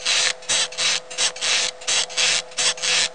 Bah c’est mon premier enregistrement confiné, soyez indulgents. Je commence à acquérir une technique de scratch pneumatique dont je ne suis pas peu fier.